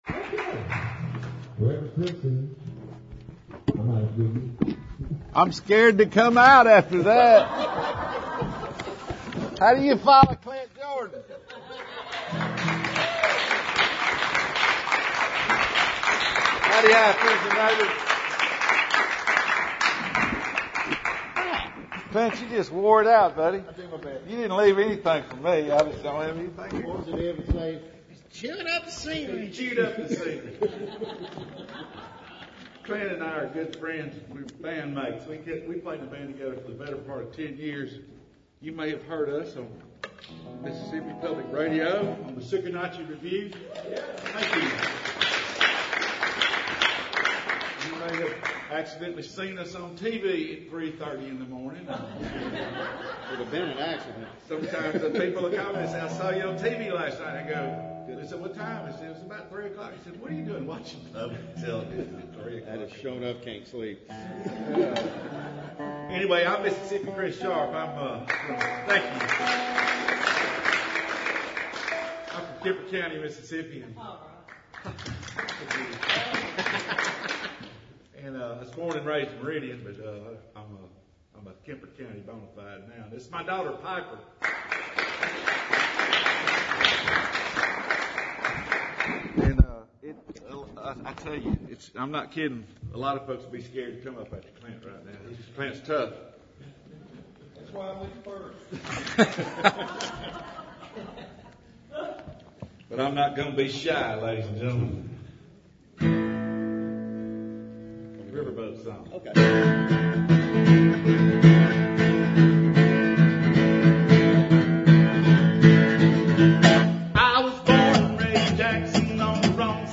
I did get an audio recording of our full show.